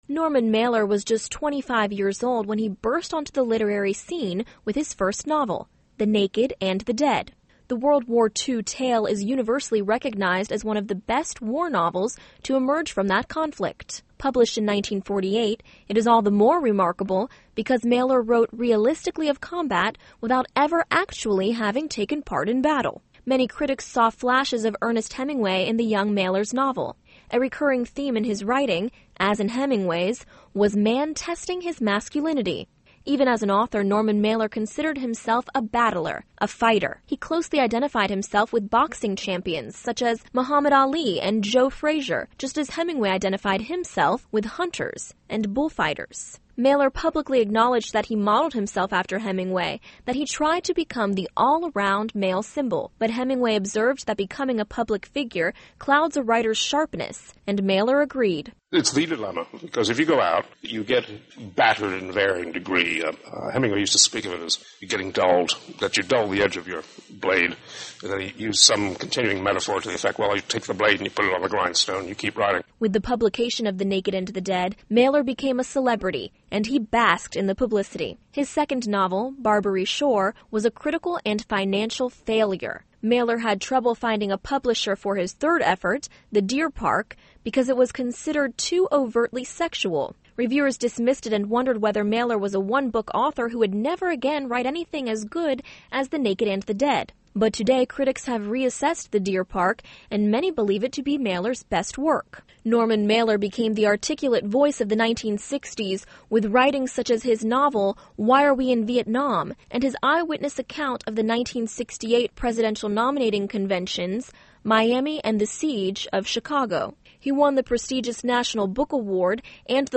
This audio report